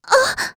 s020_Noraml_Hit.wav